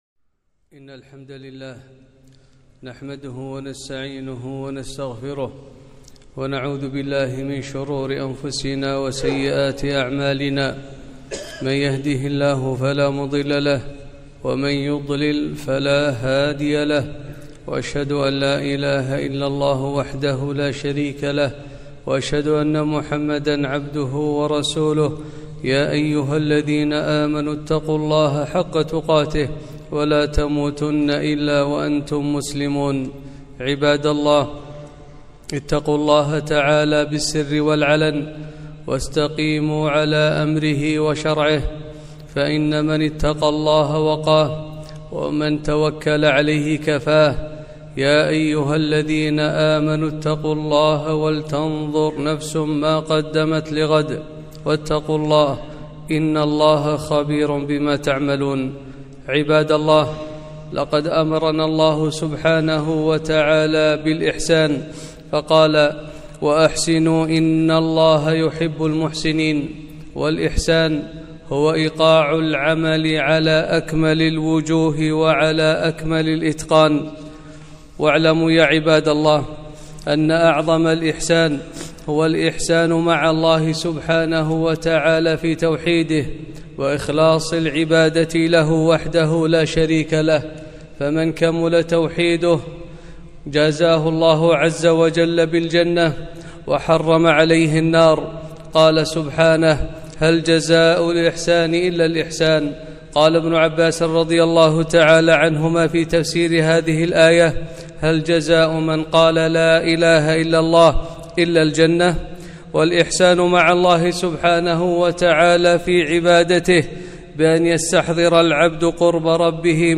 خطبة - خطورة اللسان